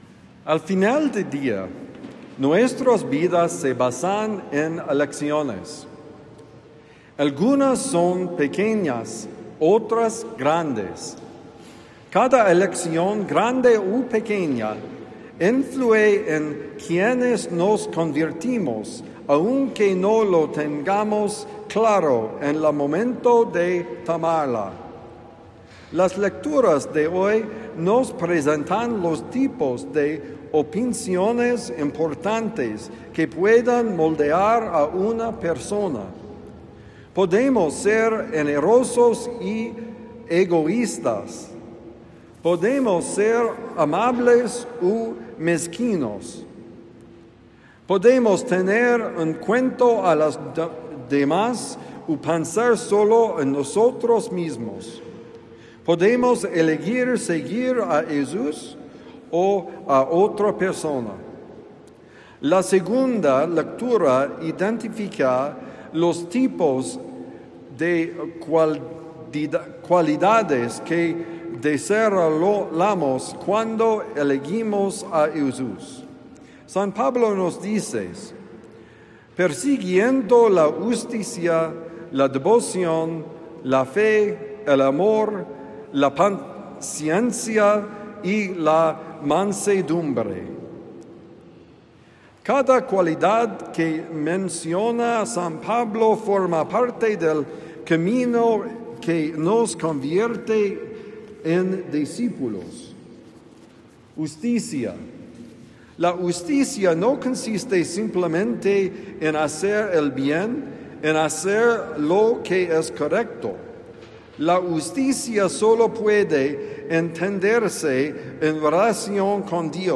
Opciones: Homilía para el domingo 28 de septiembre de 2025 – The Friar